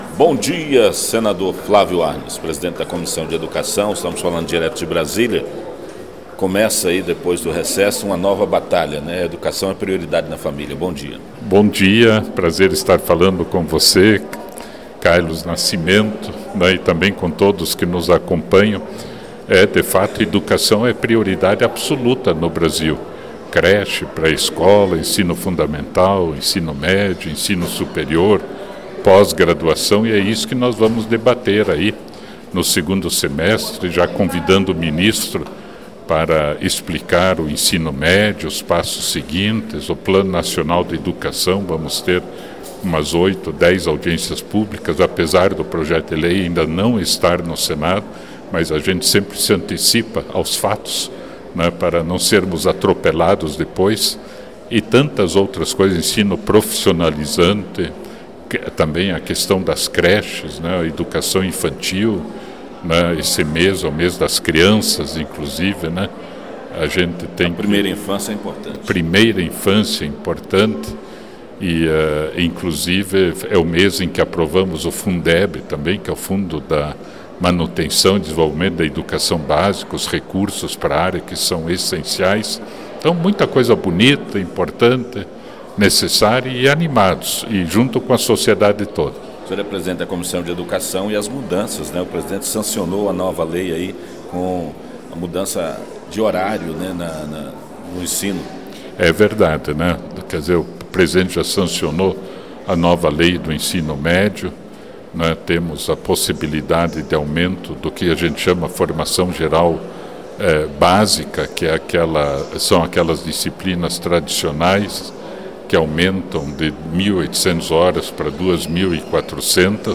arnes-entrevista.mp3